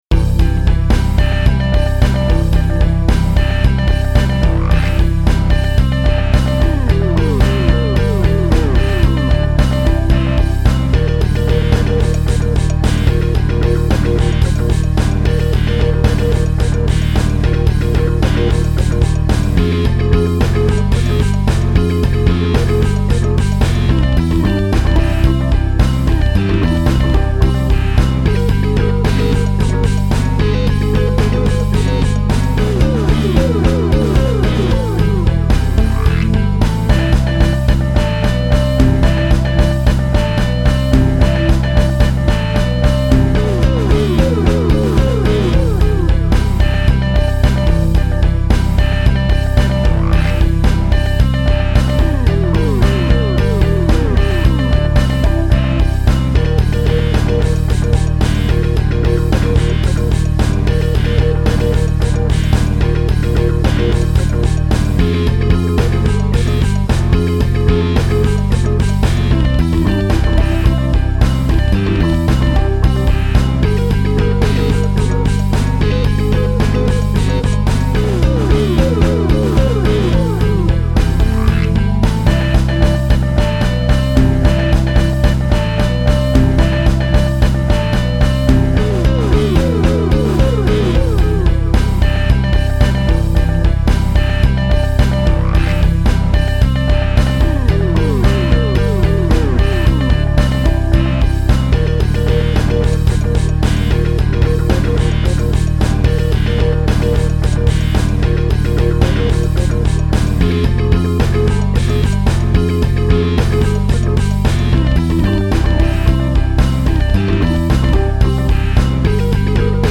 The most surprising aspect of mixing with the newly enhanced Kustom studio monitor system is that it does not require using a lot of VST signal processing plug-ins, and in fact the majority of the VST signal processing was limited to a tiny bit of optical compression or brickwall limiting, which also was the case for the Master stereo output track, where instead of needing to use a full-featured mastering suite, I only had to use a linear phase equalizer and a leveling amplifier (both from IK Multimedia), and this makes intuitive sense, because all the instruments are IK Multimedia VSTi virtual instruments, so the sample sounds were professionally recorded, hence should not need a lot of enhancing other than having a bit of FUN with reverberation and echoes on a few of the instruments .
[NOTE: This is the basic rhythm section, so there is no singing and no real lead guitar soloing, but so what .
Now that I can hear the deep bass--which is vastly important, since I am a fundamental tone hearer, hence am not fooled by the "missing fundamental" auditory illusion, which maps to mixing based on what actually is heard in the room without any trickery or deception--it will take a bit of experimenting to fine-tune the new way of mixing and mastering, but I am very happy with the initial results, for sure .